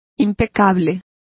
Complete with pronunciation of the translation of flawless.